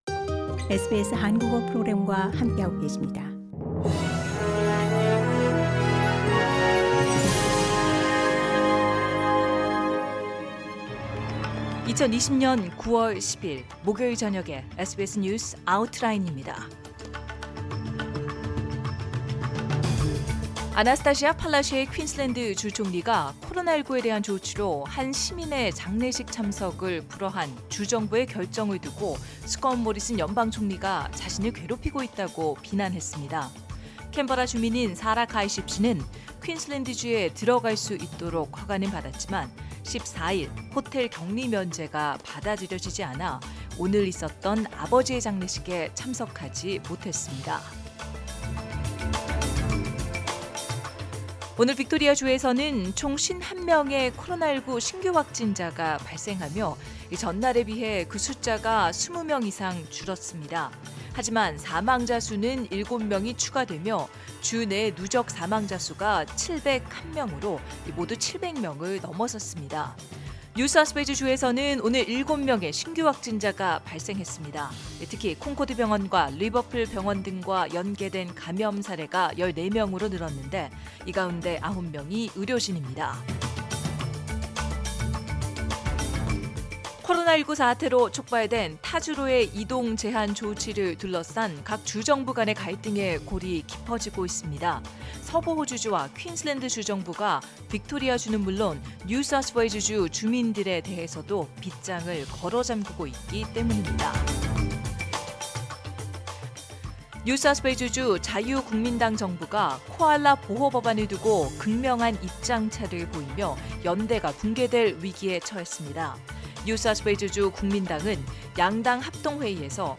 [SBS News Outlines] 2020년 9월 10일 저녁 주요 뉴스